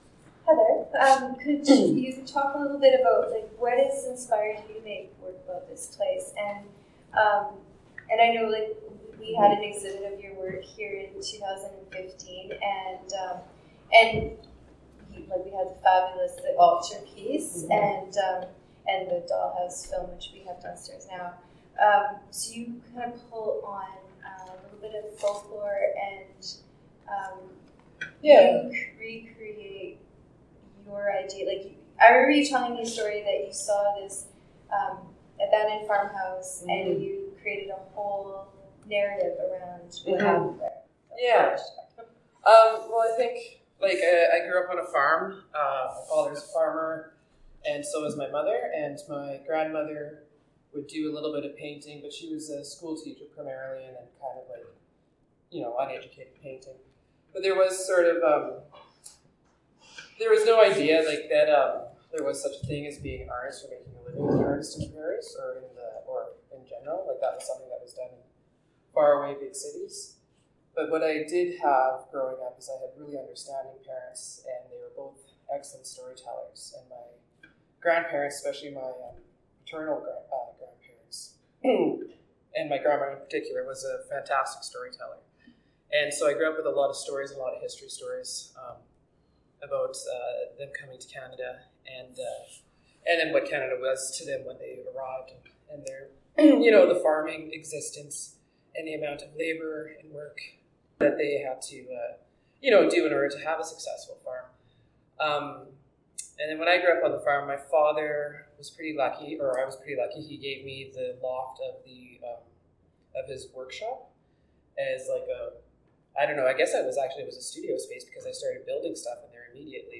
Artist Interview.